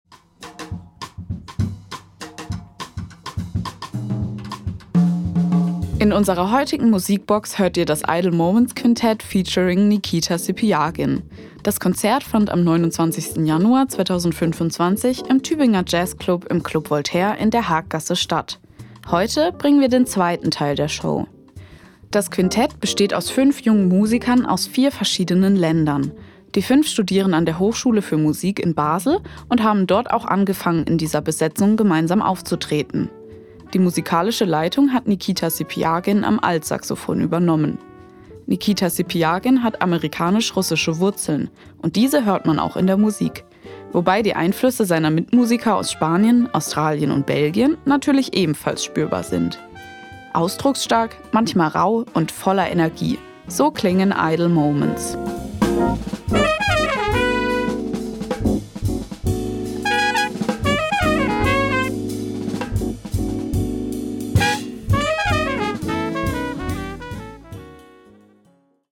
Altsaxofon
Trompete
Nord-Piano
Bass
Schlagzeug